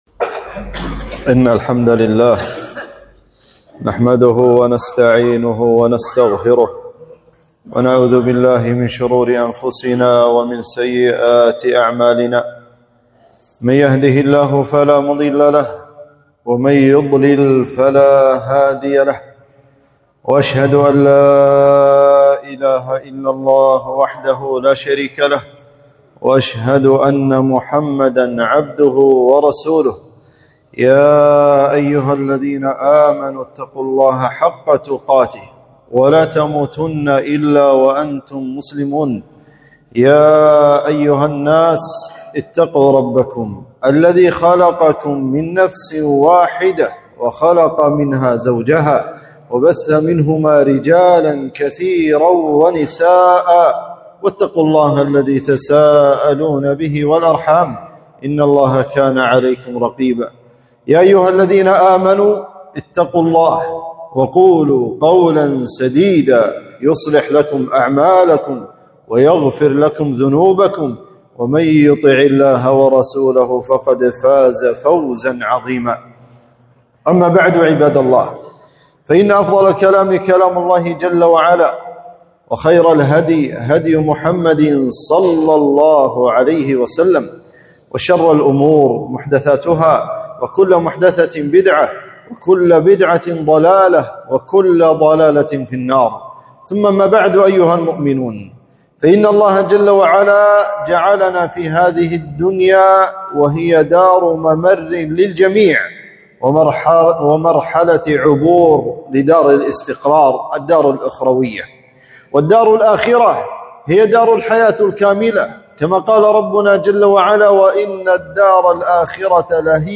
خطبة - استغلال شهر شعبان